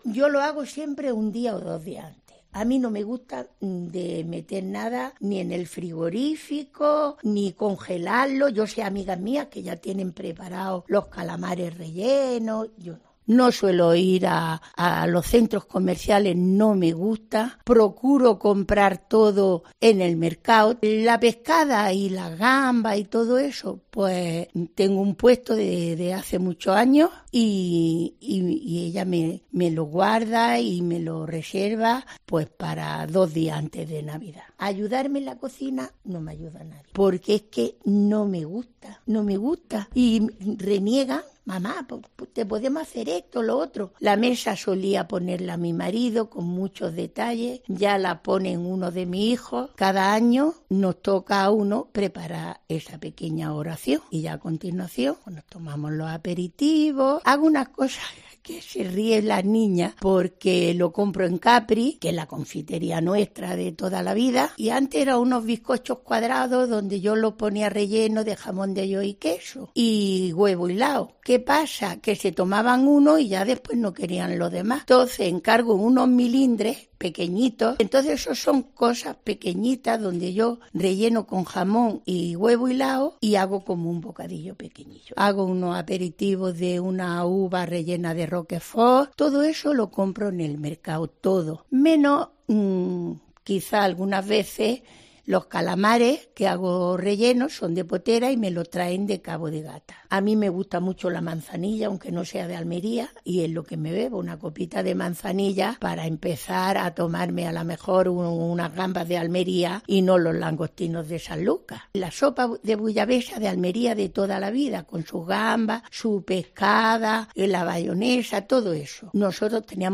Y lo quiere hacer con ocho reportajes en los que ocho familias son las protagonistas de nuestra “Mesa de Navidad”.